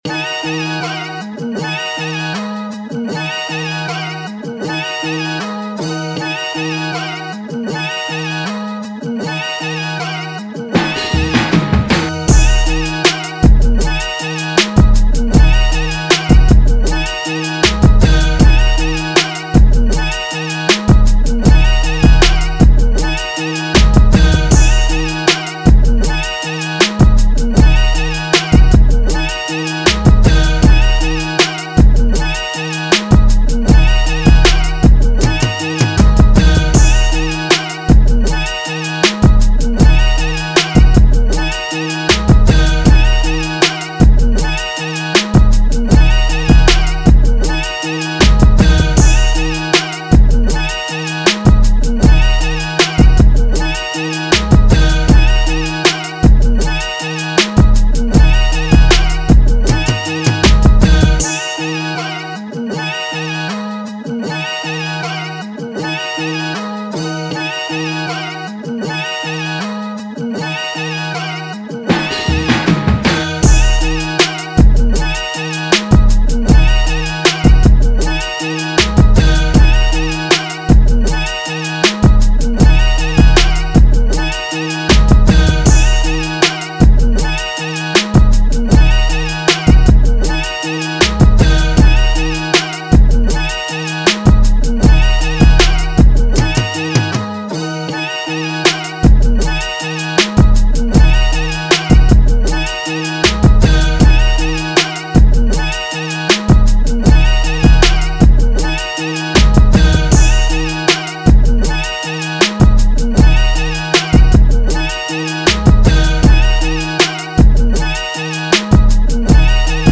2019 in Hip-Hop Instrumentals